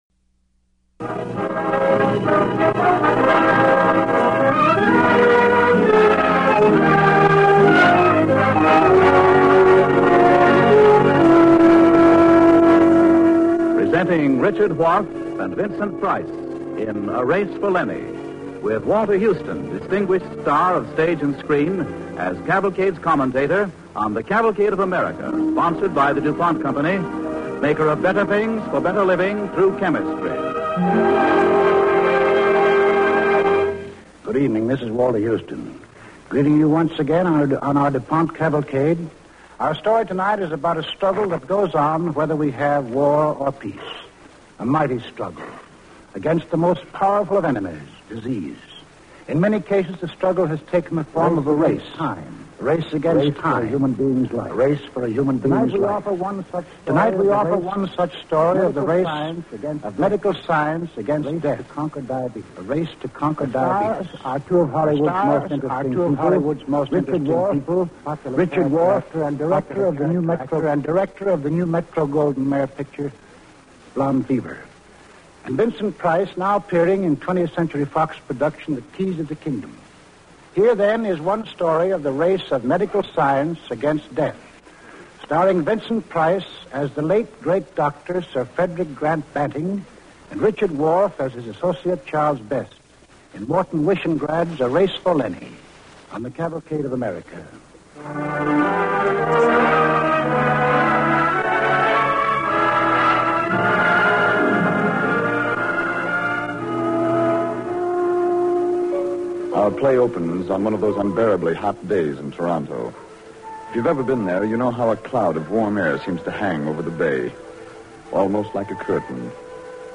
starring Vincent Price with host Walter Houston